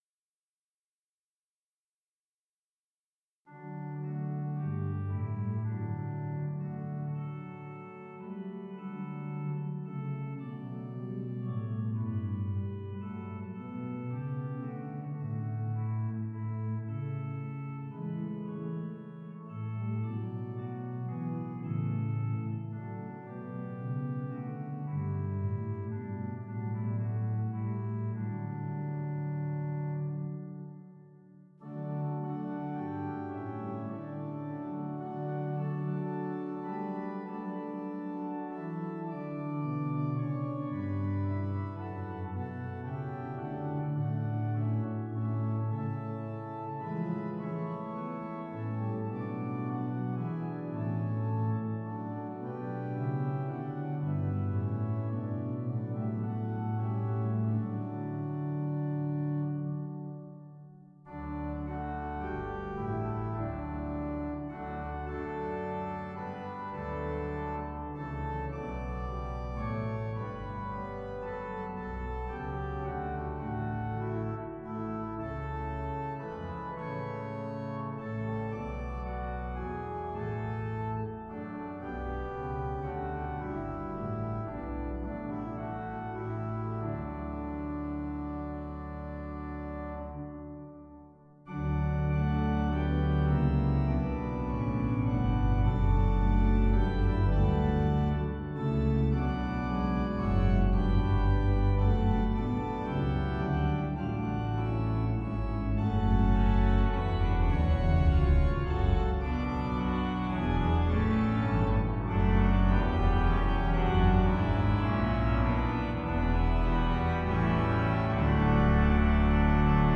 Hymn When I survey the wondrous cross